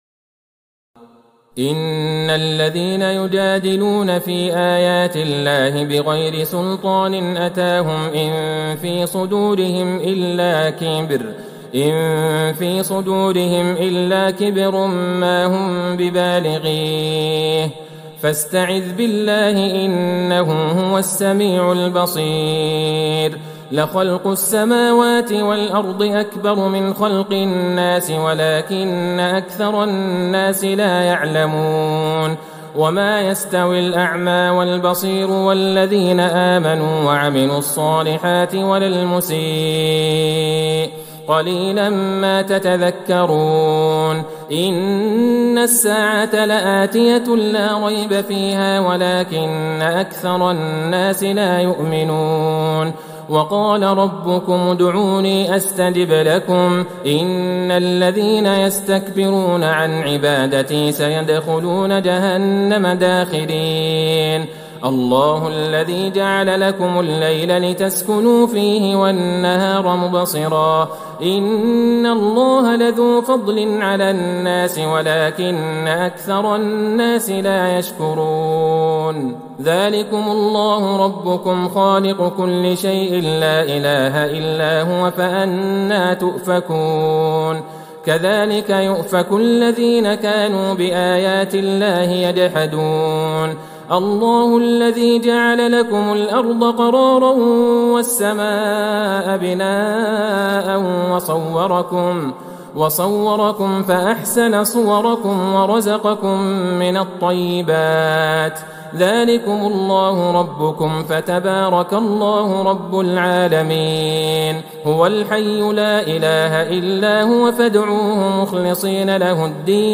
تراويح ٢٦ رمضان ١٤٤١هـ من سورة غافر { ٥٦- النهاية } وفصلت { ١-٢٥ } > تراويح الحرم النبوي عام 1441 🕌 > التراويح - تلاوات الحرمين